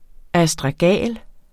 Udtale [ asdʁɑˈgæˀl ]